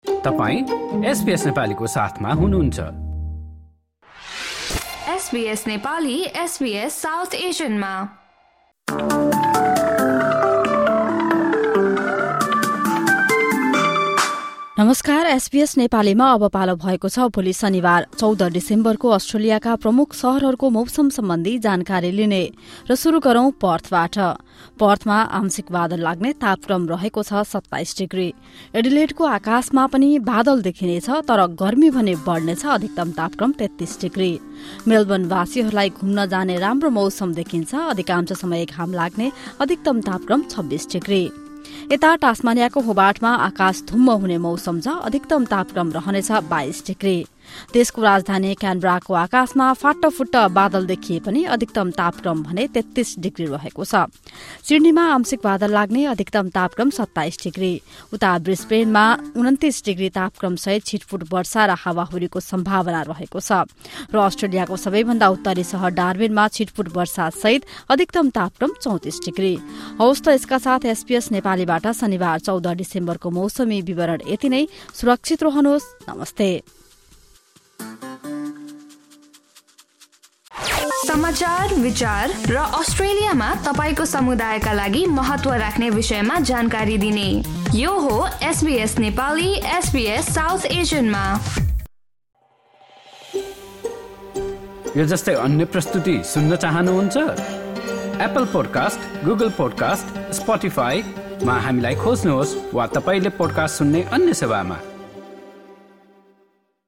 Australian weather update in Nepali for Saturday, 14 December 2024.